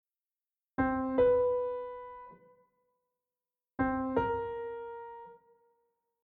Before we move any further take a listen to the intervals and hear the difference…